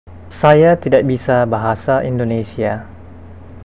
speaker.gif (931 bytes) Click on the word to hear it pronounced.